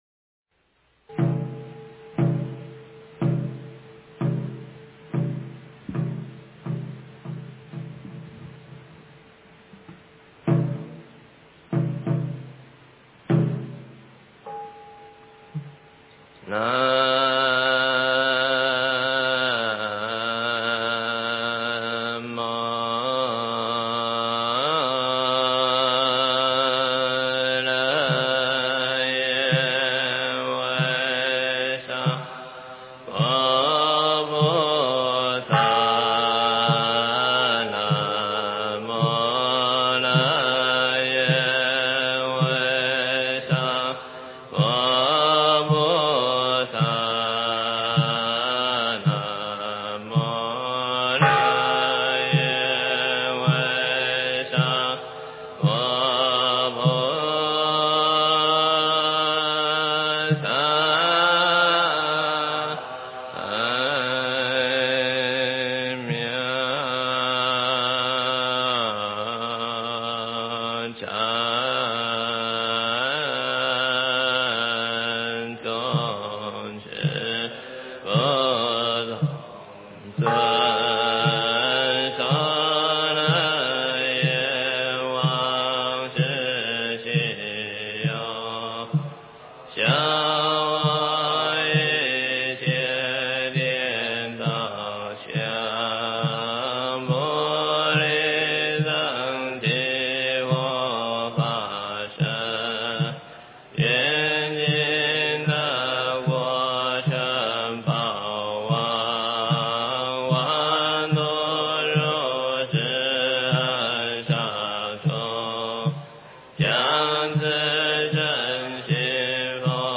早课（咒语）--台湾高雄文殊讲堂 经忏 早课（咒语）--台湾高雄文殊讲堂 点我： 标签: 佛音 经忏 佛教音乐 返回列表 上一篇： 净宗早课--未知 下一篇： 炉香赞--文殊院 相关文章 感恩一切--佛教音乐 感恩一切--佛教音乐...